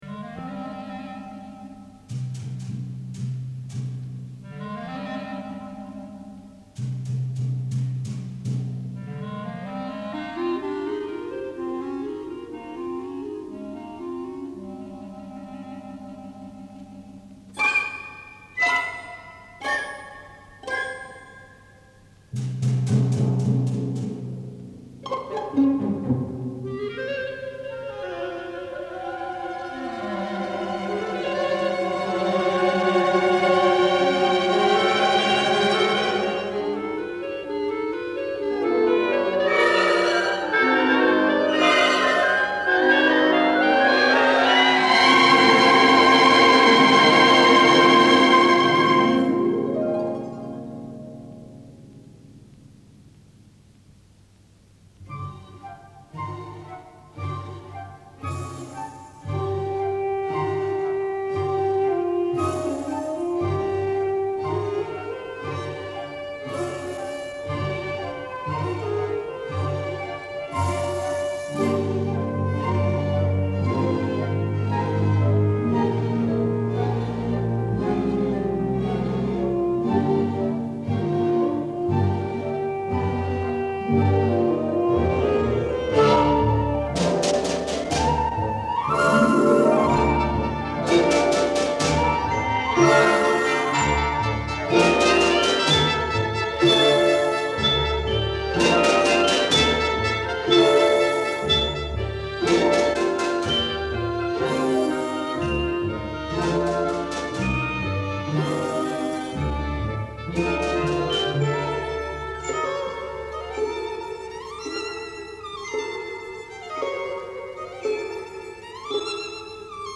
管弦乐队作品